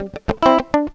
GTR 98 AM.wav